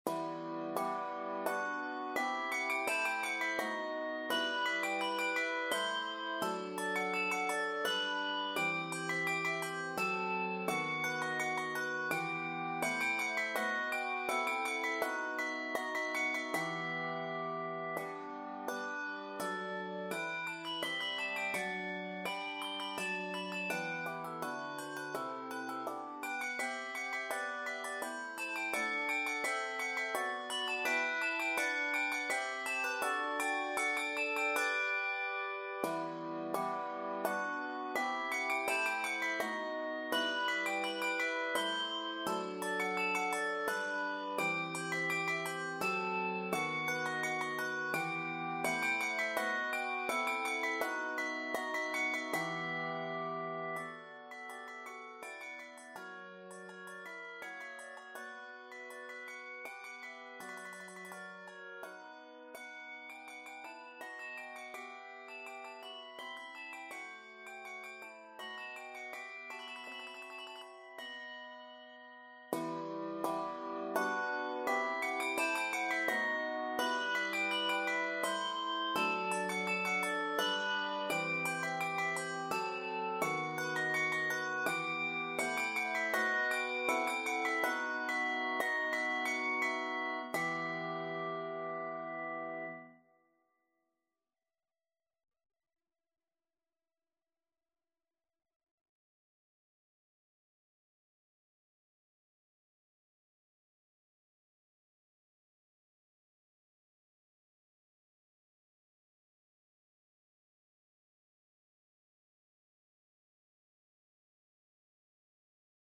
Key of e minor.